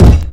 barricade.wav